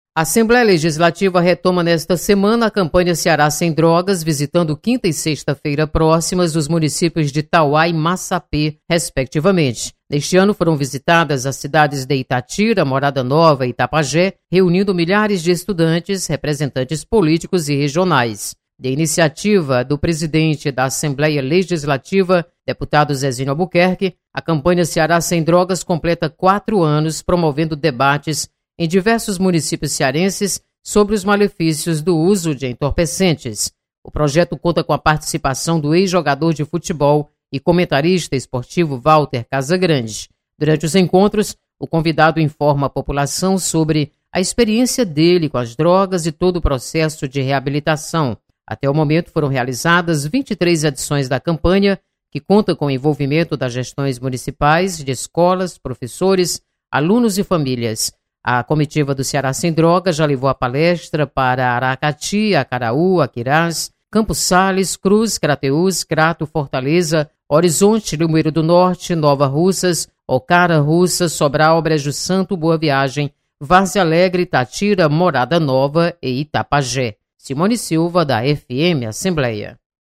Você está aqui: Início Comunicação Rádio FM Assembleia Notícias Ceará sem Drogas